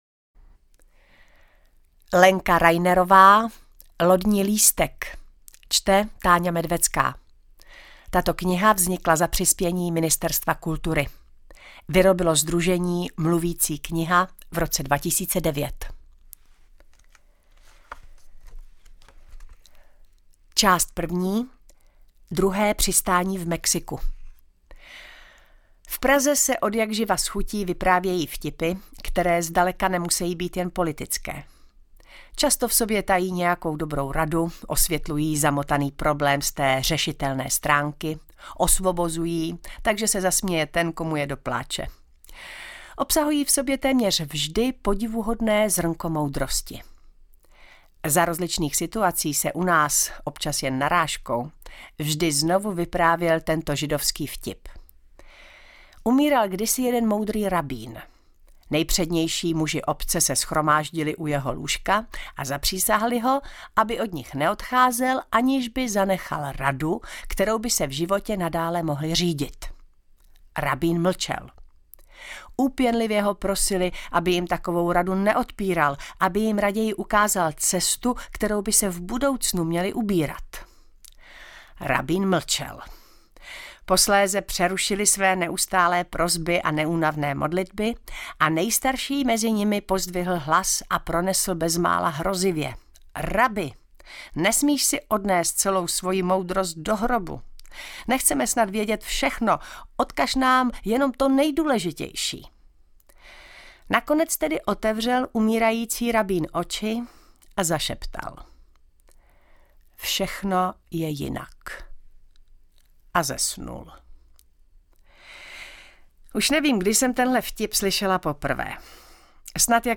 Lodní lístek Autor: Lenka Reinerová Čte: Táňa Medvecká Vzniklo za přispění: Dvě dosud česky nevydané rozsáhlé povídky z Marseille a z Mexika.